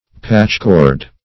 patchcord \patch"cord\ n.